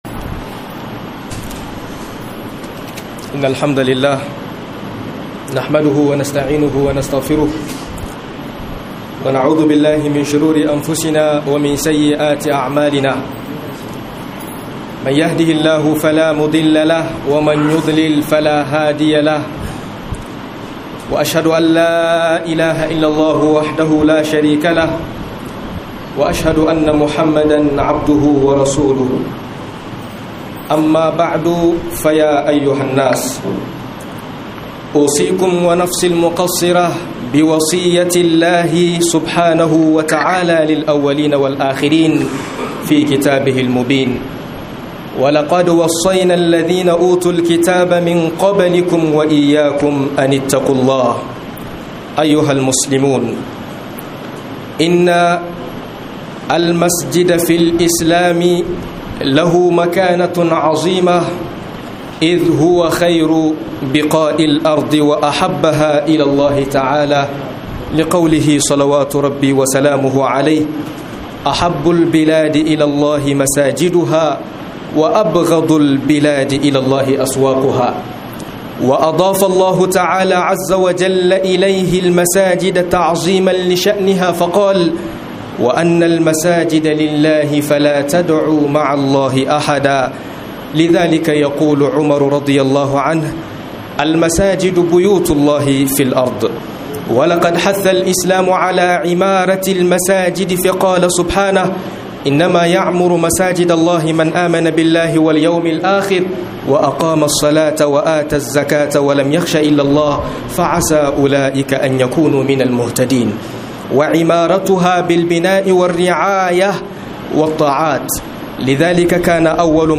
Mastayin Masallaci Da Aykin sa - MUHADARA